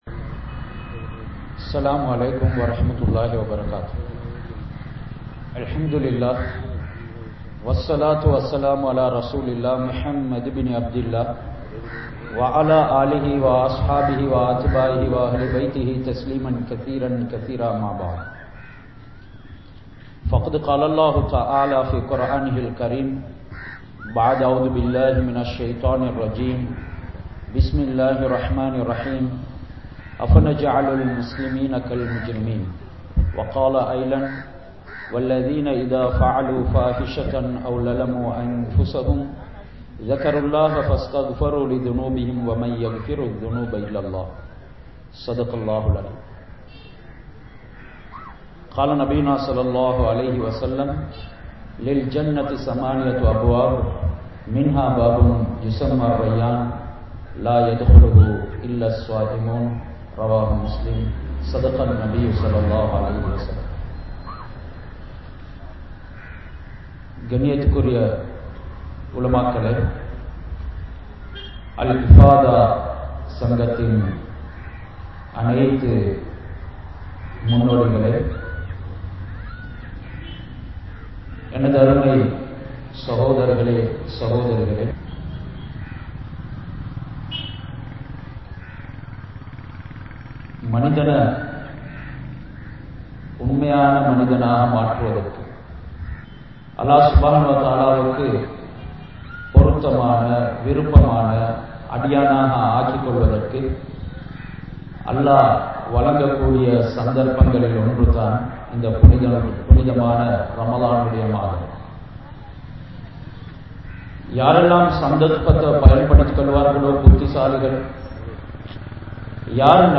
Allah`vai Payanthu Vaalungal (அல்லாஹ்வை பயந்து வாழுங்கள்) | Audio Bayans | All Ceylon Muslim Youth Community | Addalaichenai